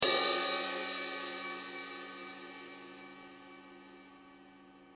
ride.mp3